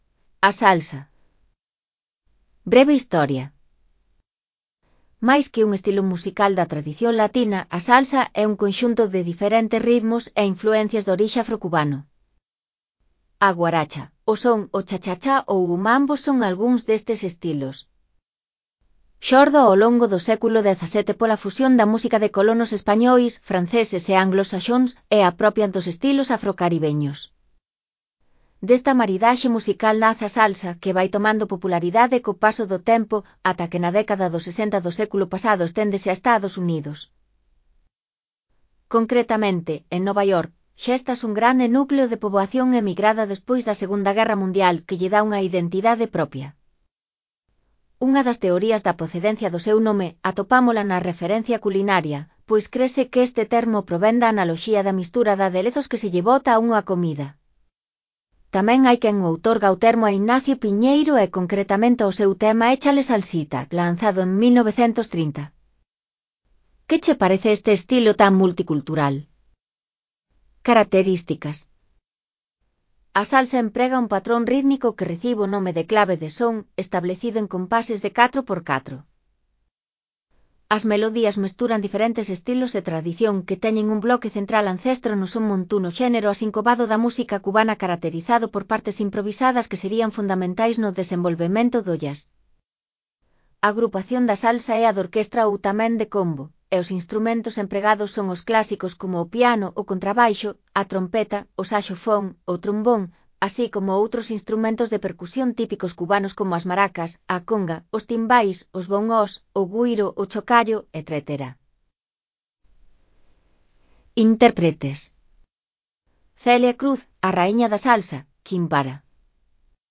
A Salsa
A salsa emprega un patrón rítmico que recibe o nome de “clave de son”, establecido en compases de 4/4.
AUD_MUD_6PRI_REA02_A_SALSA_V01.mp3